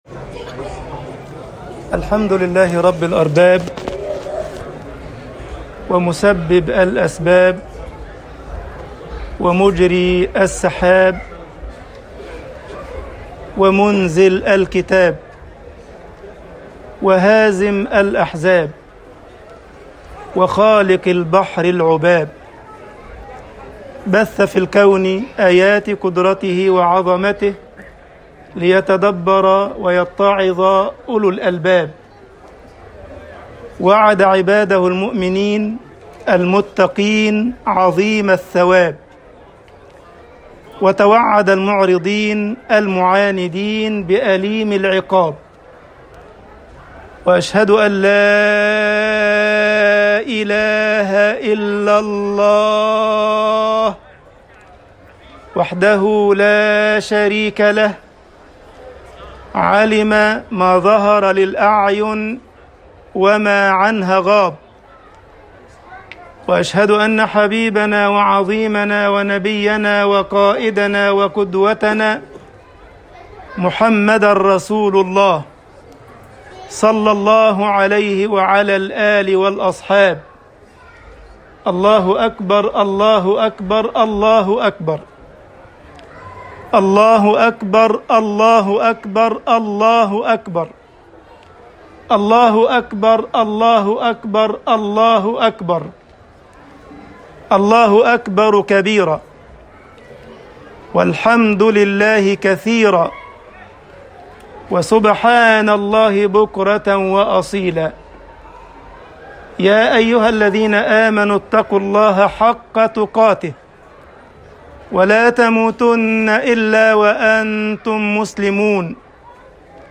خطبة عيد الأضحى 1444هـ بعنوان